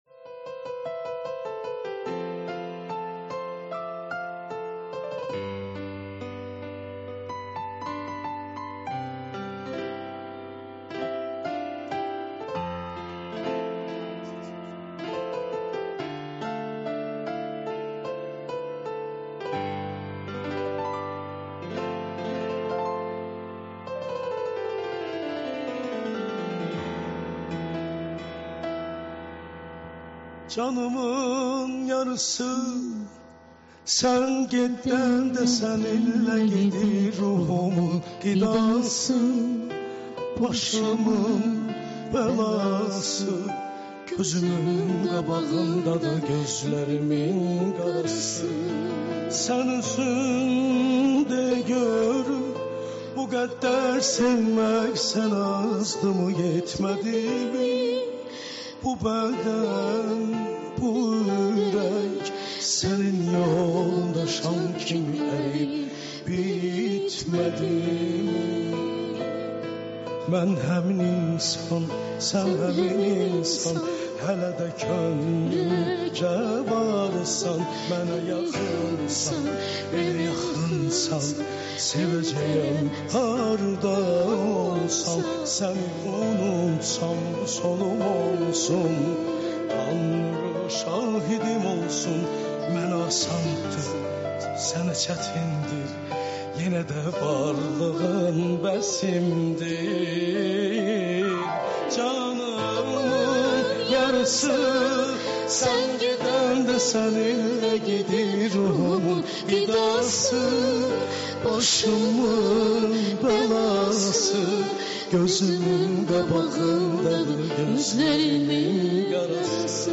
CANLI İFA